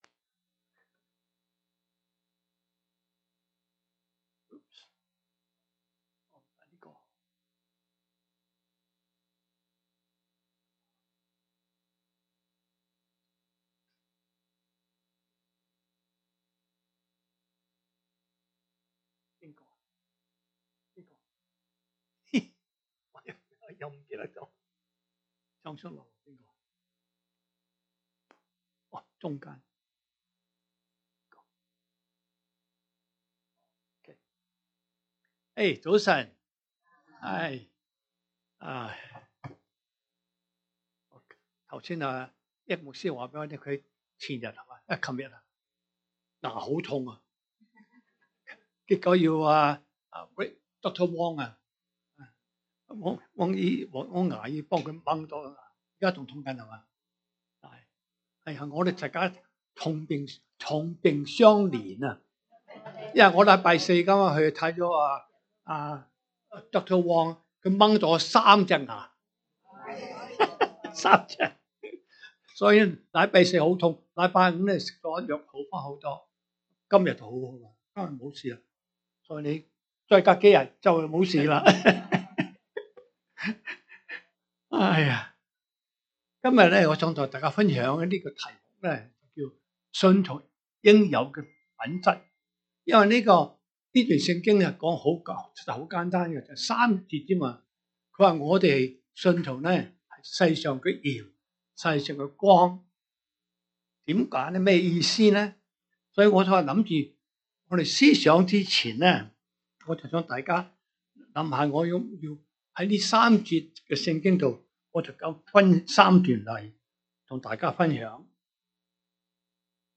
Sermons by CCCI